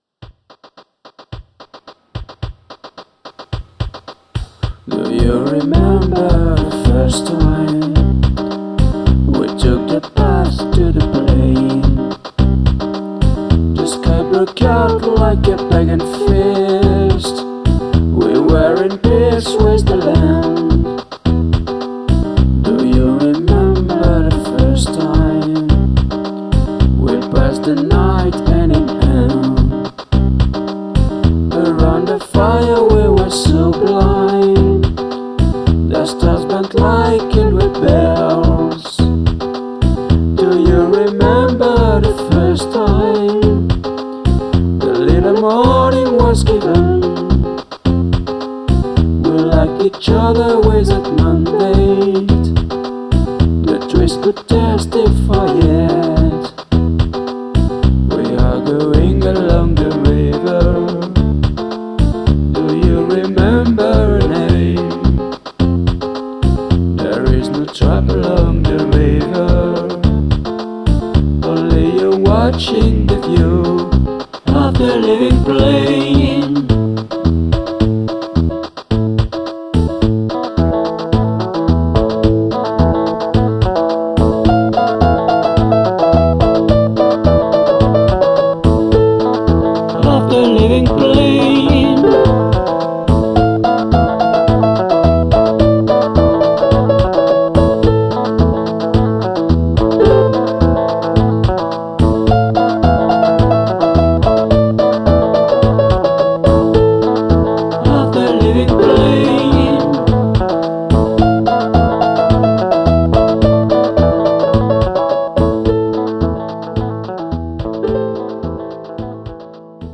faussement gentillet, gai et nostalgique à la fois